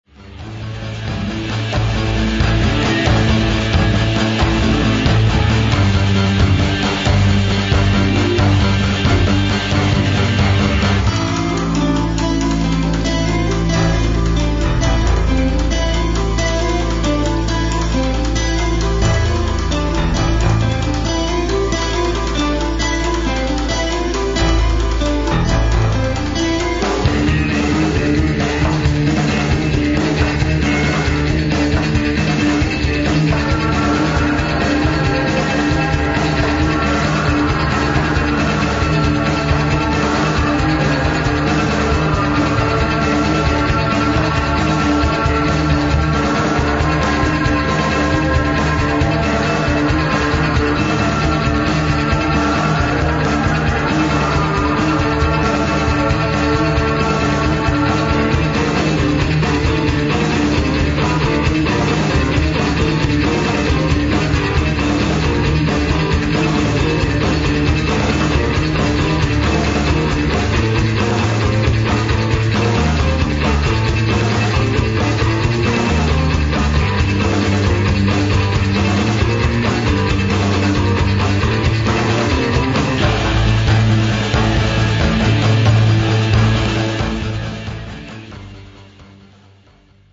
accordion, vocals
keyboards, vocals
electric and acoustic guitar
clarinet, alto sax
soprano, alto, tenor and bariton sax
ダークかつヘヴィ。
ヘヴィ なギターと怪しいキーボード、変な音のベース、爆音ドラム、どれをとっても一筋 縄ではいかない演奏ばかりです。